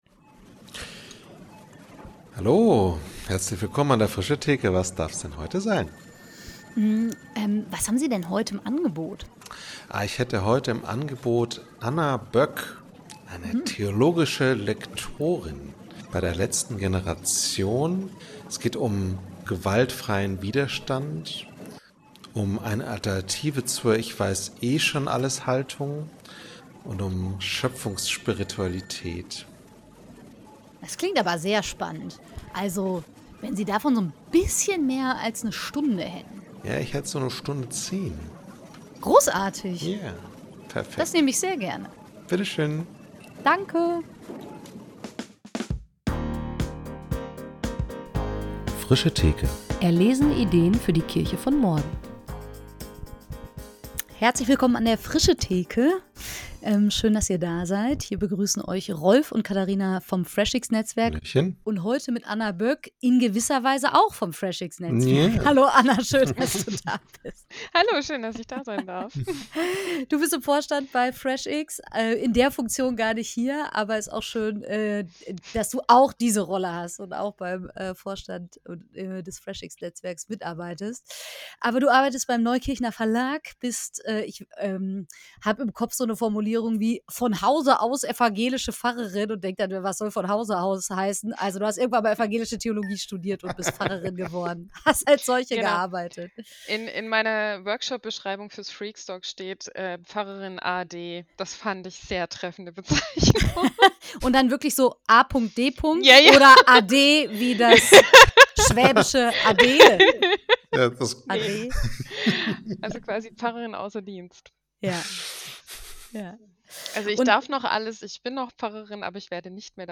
Evangelische Kirchengemeinde Starnberg Predigten 16.